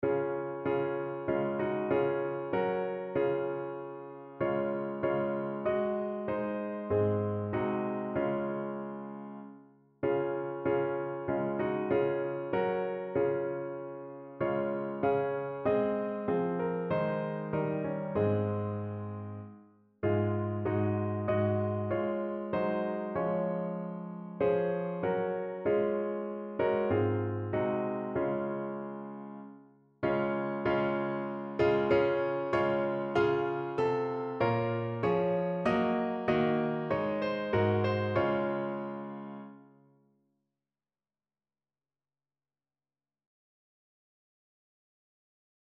Evangeliumslieder